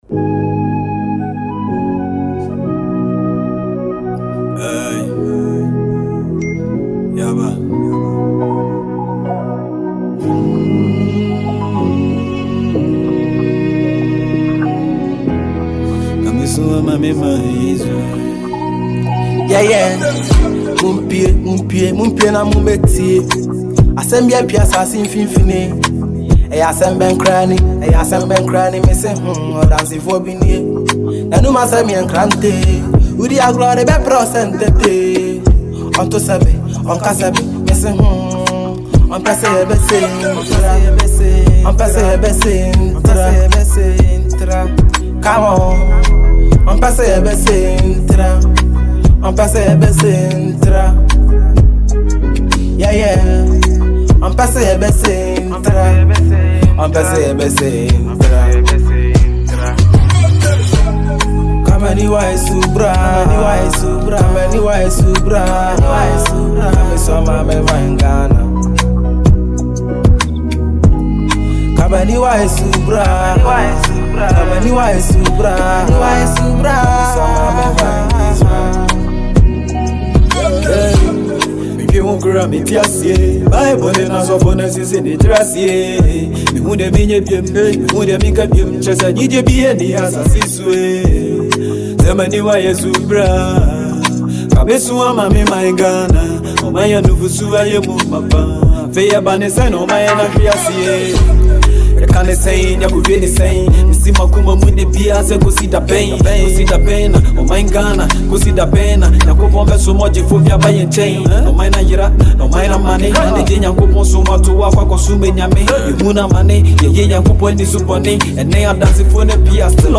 pure gospel tune